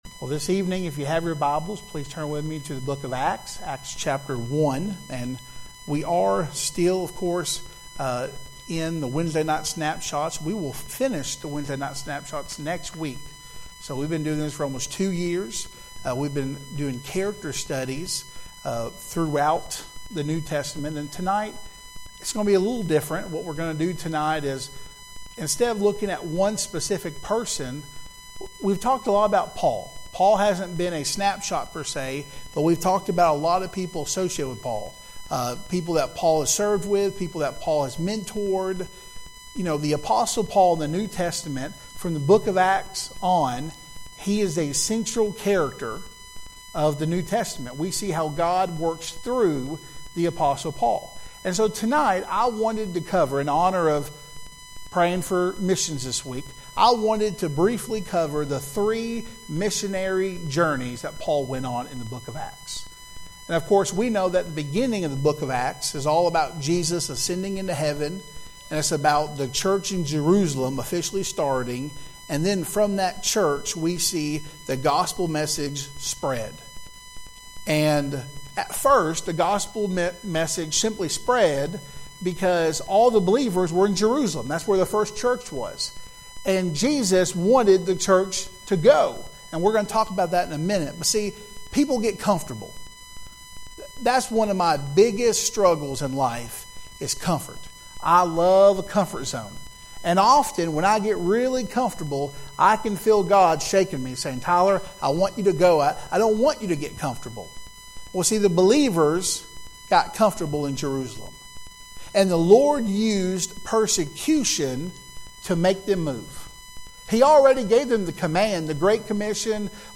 Sermons by SFBC